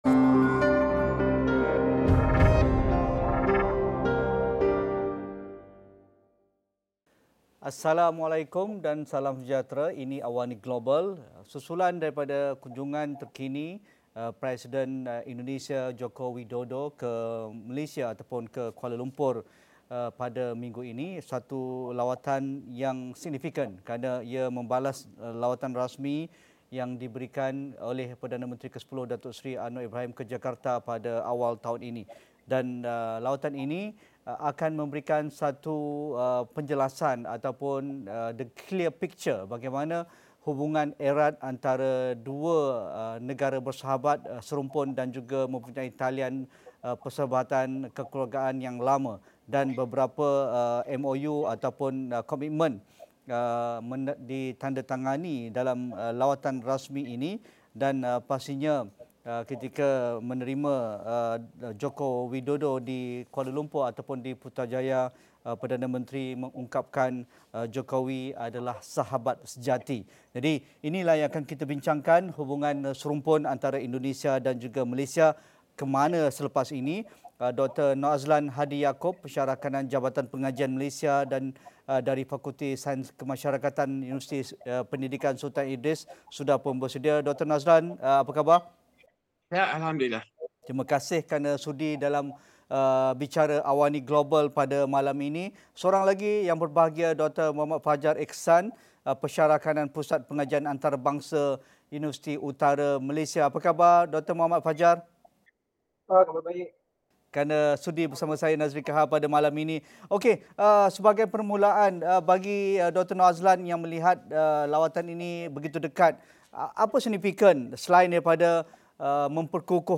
Analisis dan diskusi mengenai era baharu hubungan Malaysia-Indonesia yang digambarkan sempena lawatan balas Presiden Jokowi ke Malaysia. Sejauhmanakah komitmen kedua-dua negara bekerjasama menyelesaikan isu tertunggak dan meneroka potensi besar kedua-dua negara.